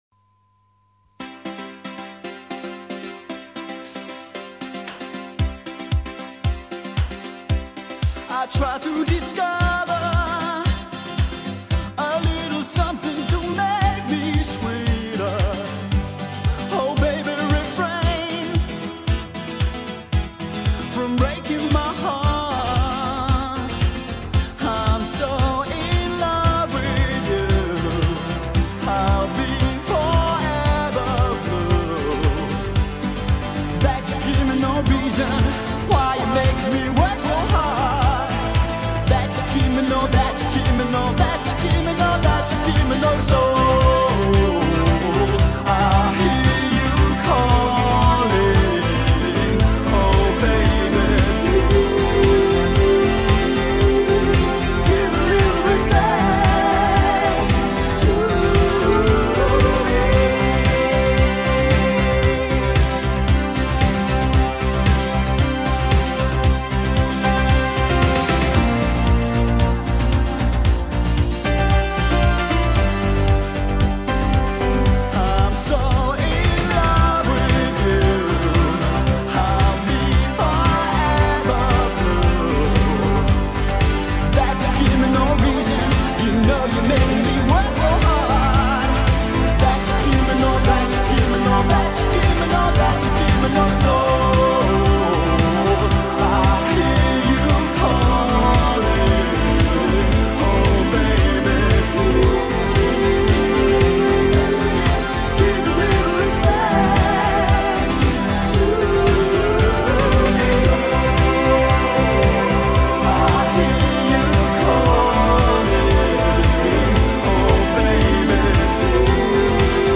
BPM114--1
Audio QualityPerfect (High Quality)